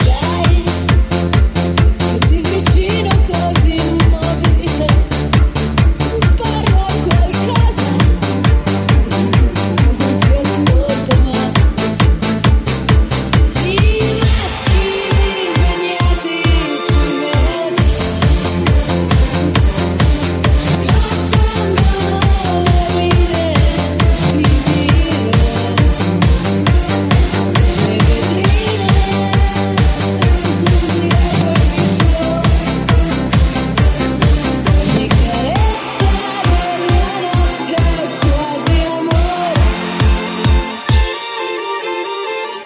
Genere: pop italiano